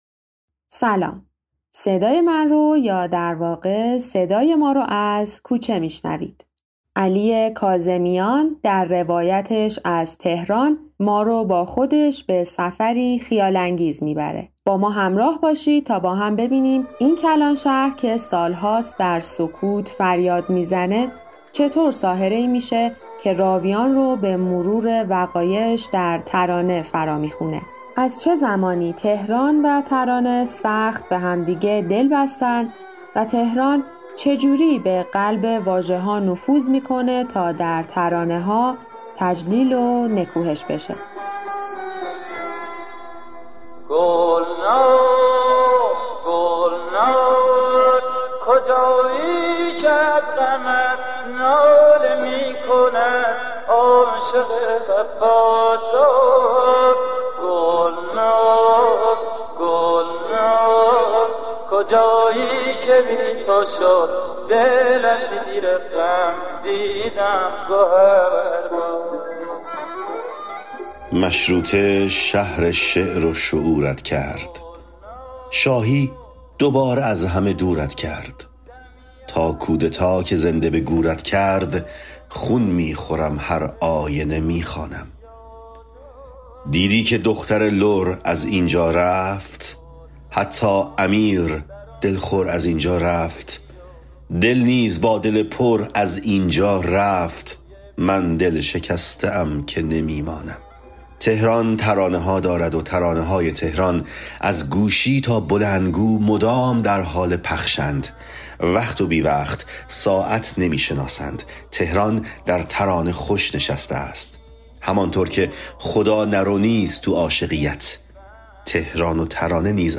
ضبط: آدالان استودیو